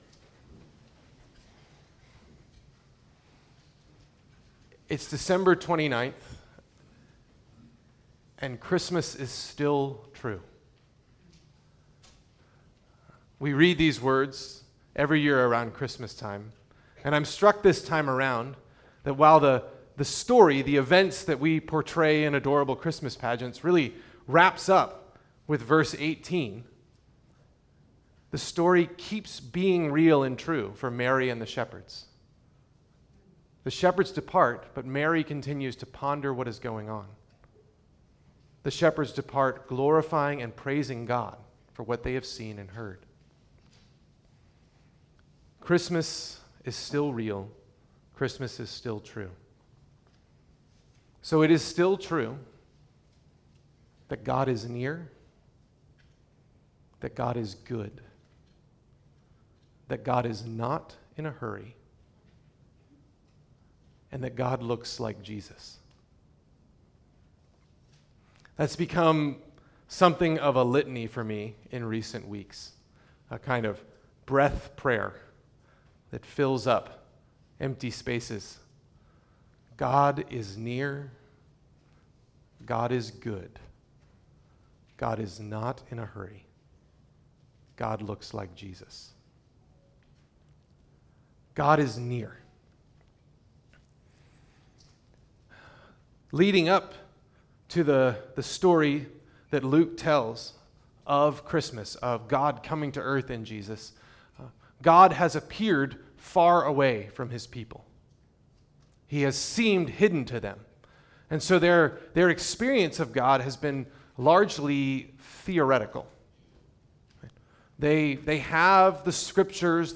Anglican Communion Service